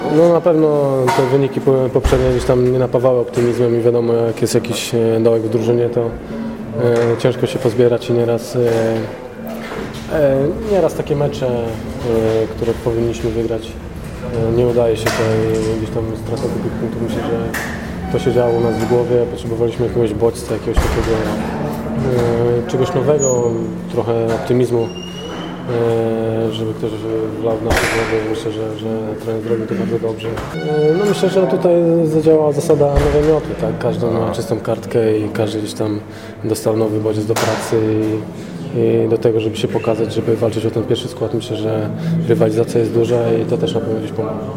Konferencja prasowa i wywiady po meczu Cracovia – Pogoń Szczecin